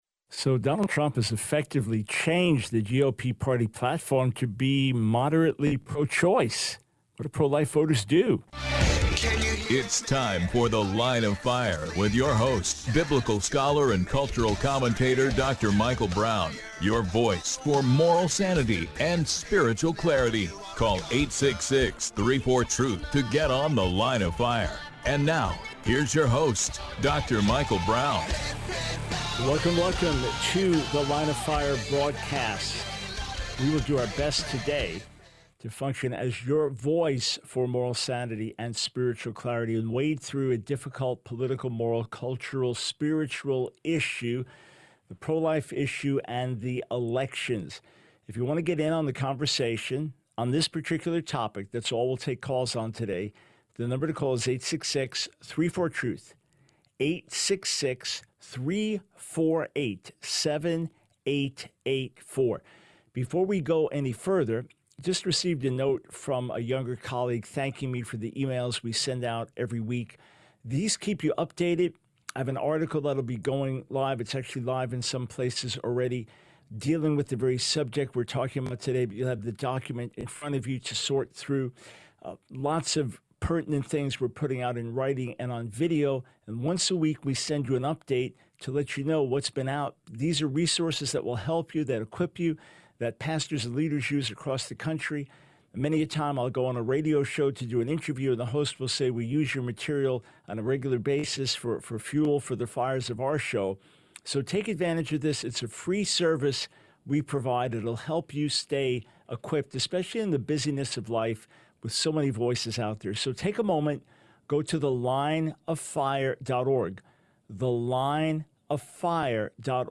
The Line of Fire Radio Broadcast for 07/10/24.